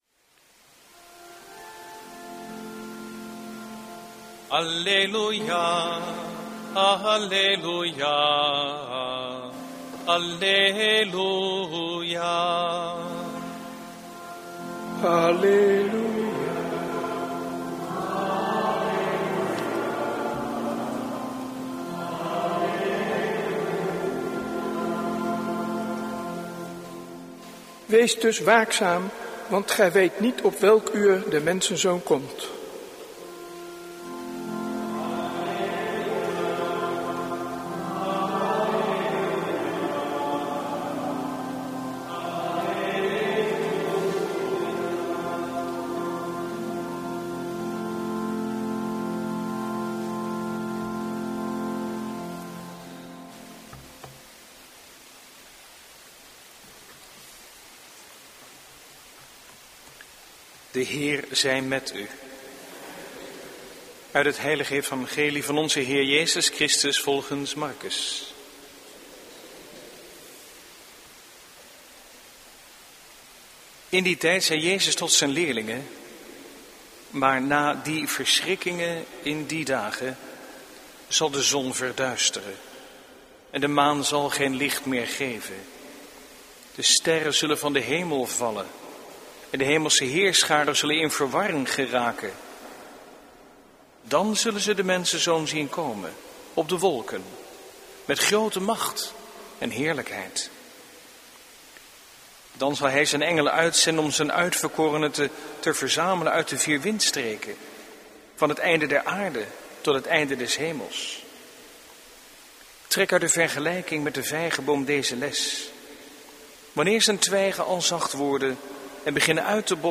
Eucharistieviering beluisteren vanuit de H. Willibrordus te Wassenaar (MP3)